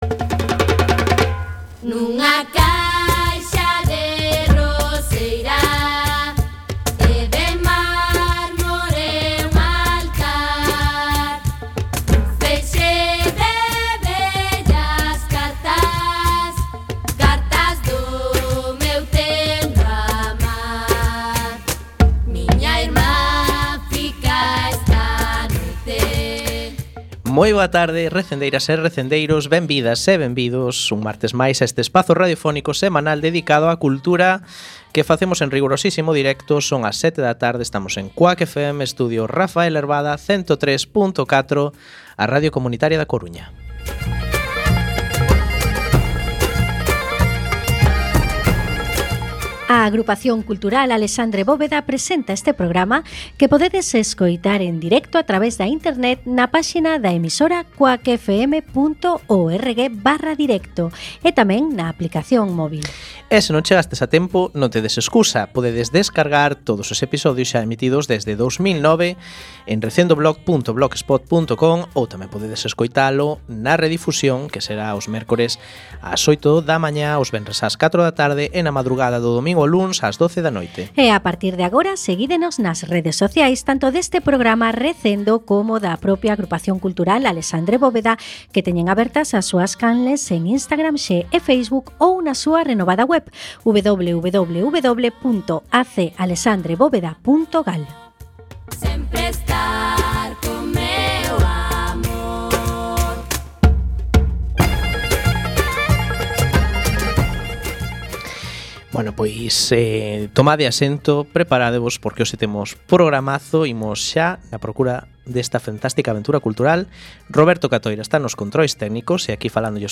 17x16 Entrevista